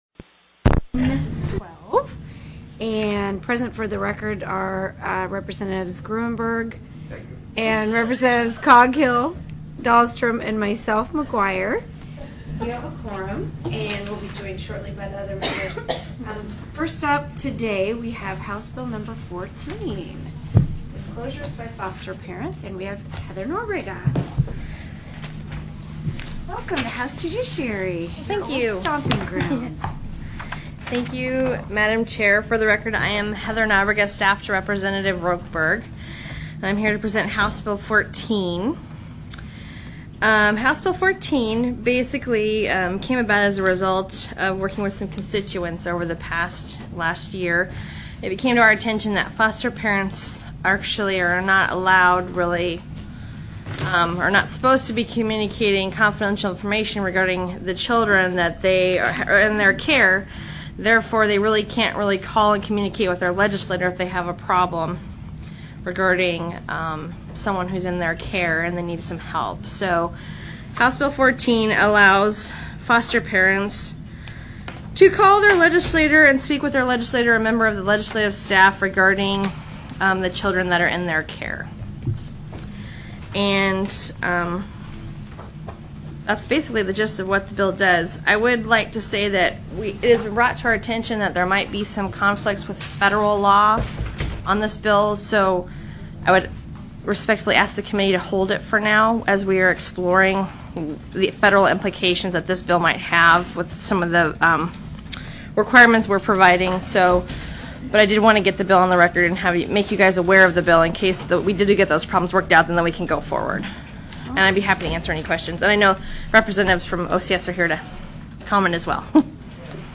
TELECONFERENCED
ALASKA STATE LEGISLATURE HOUSE JUDICIARY STANDING COMMITTEE